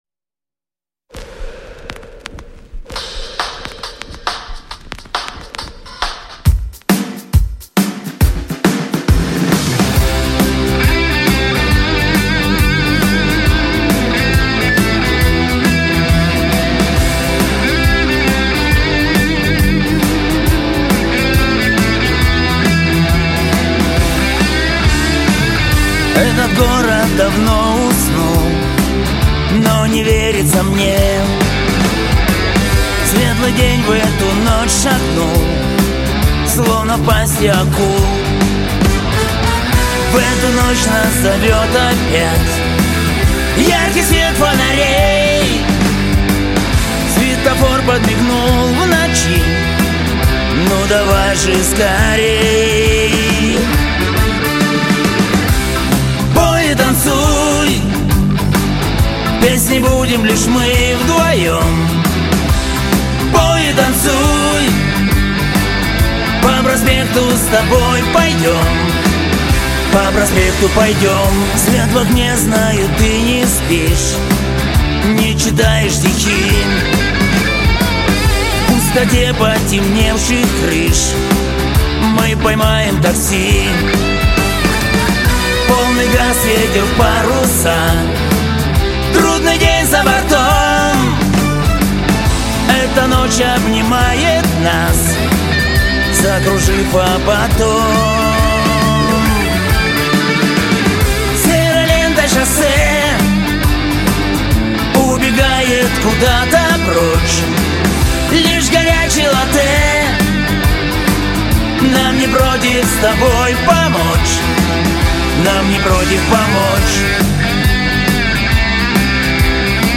Навыки Игра на музыкальных инструментах Клавишные Авторские таланты Сочиняю стихи Пишу песни Пишу музыку Певческий голос Тенор Контакты Страна Россия Регион Брянская обл.